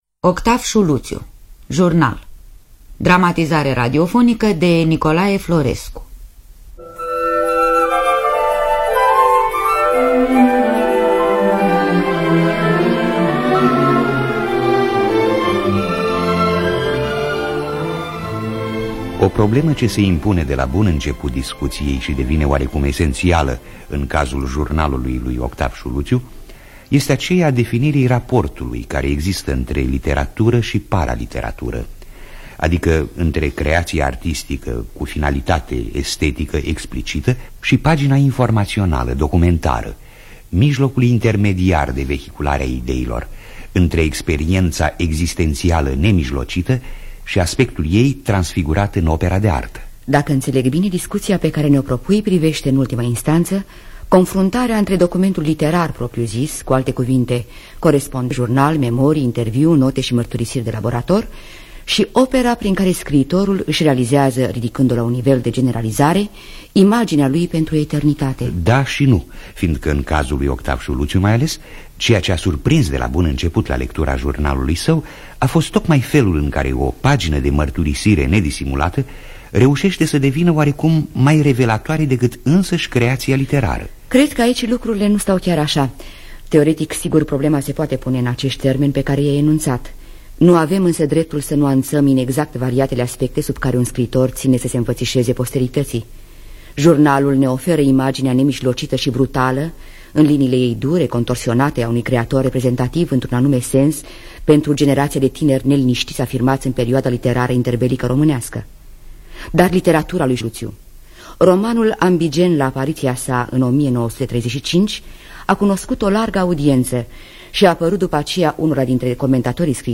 Scenariul radiofonic